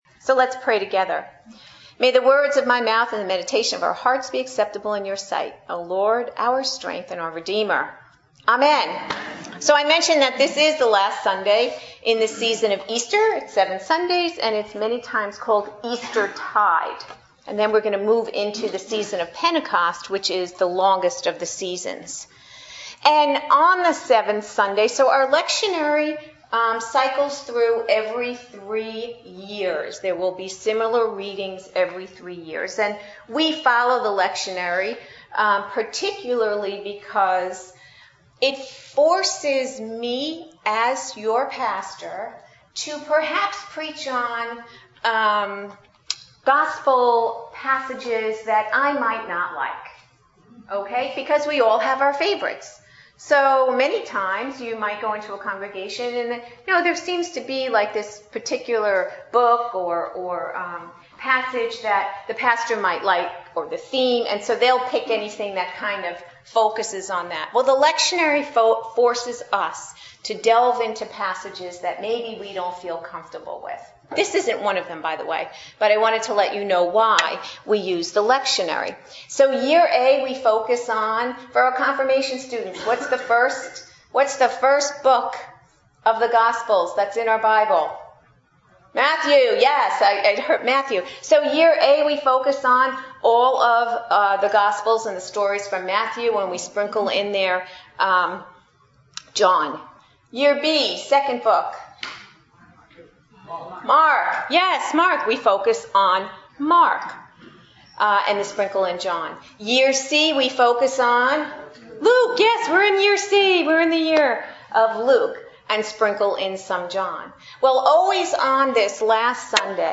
Adult Sermons